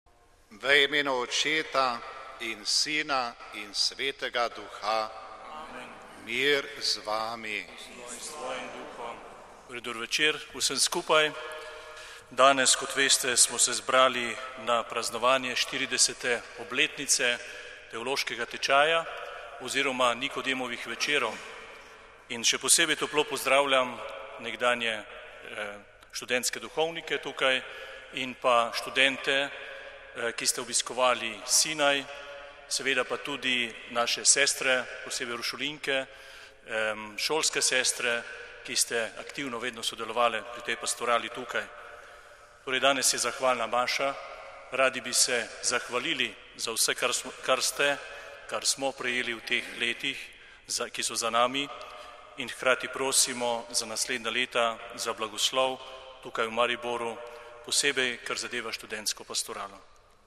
MARIBOR (četrtek, 17. november 2016, RV) – Nocoj v Mariboru praznujejo 40. obletnico Nikodemovih večerov. Mariborski nadškof in metropolit Alojzij Cvikl je ob 18.30 v mariborski stolnici vodil somaševanje nekdanjih študentskih duhovnikov.
študentski duhovnik